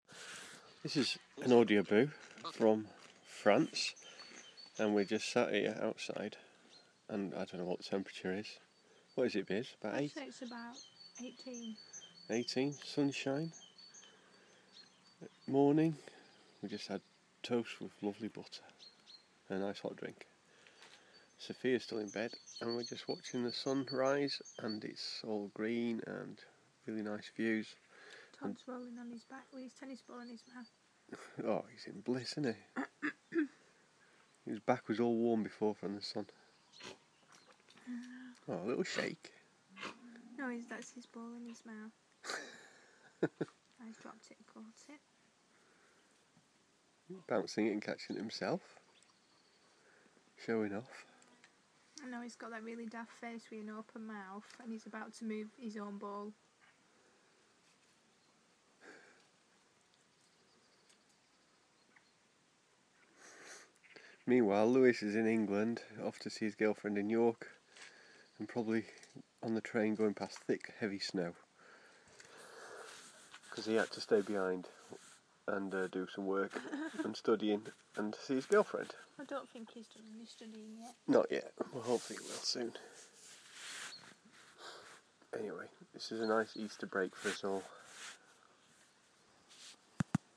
Just a typical morning sat outside our house in Southwest France listening to the birdsong and enjoying the quiet coffee before the busy day begins. 6 acres of grounds to cut grass to trim and gite guests to keep happy!